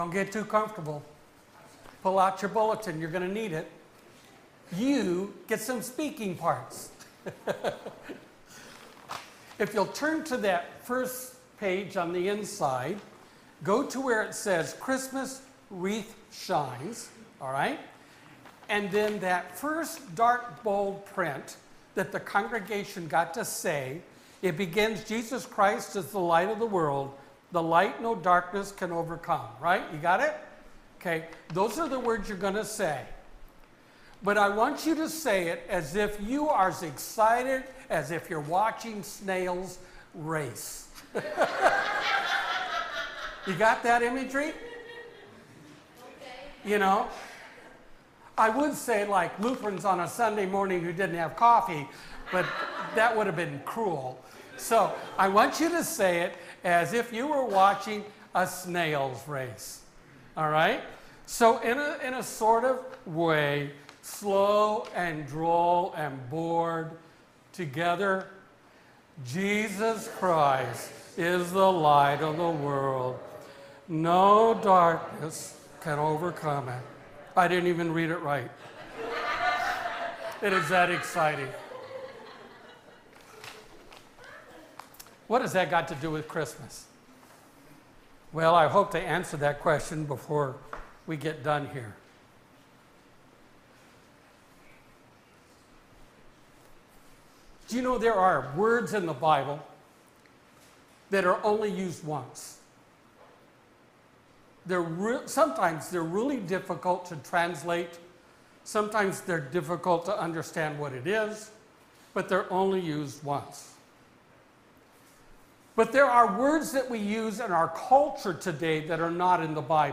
Christmas Candlelight Sermon